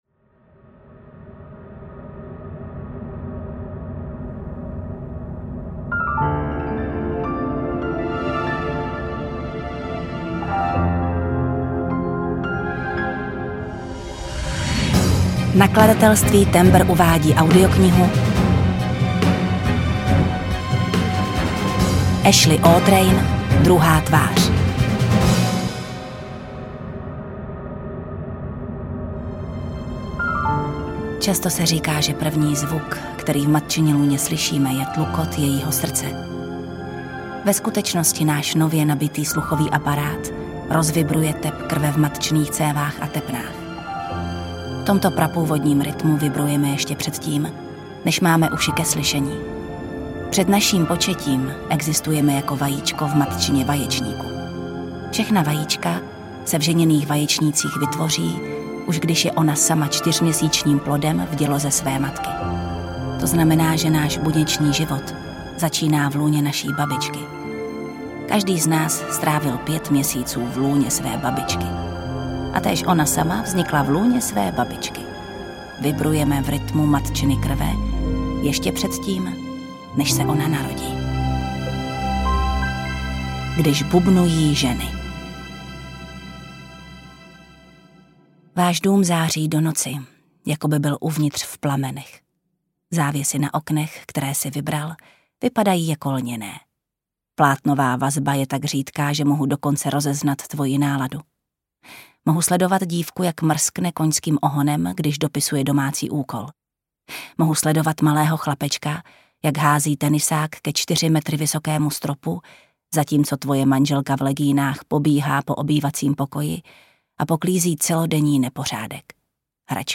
Druhá tvář audiokniha
Ukázka z knihy
• InterpretJana Stryková